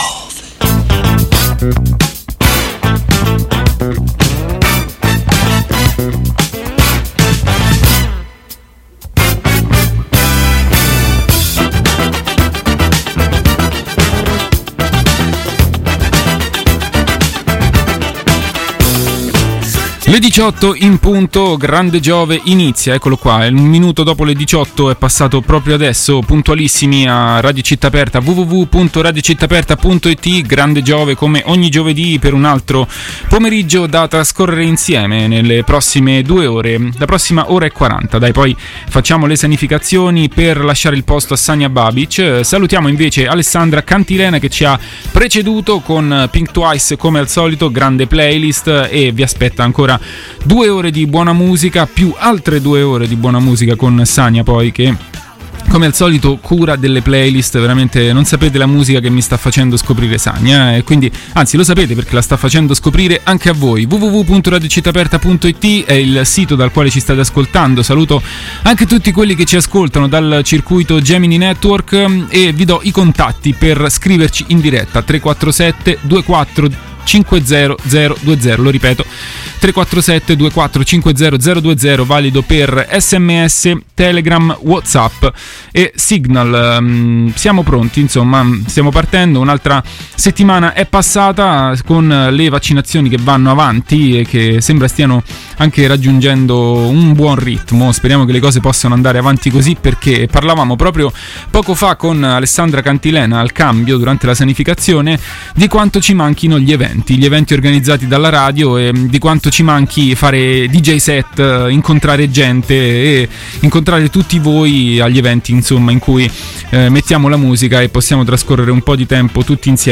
Questo e molto altro, con il solito accompagnamento musicale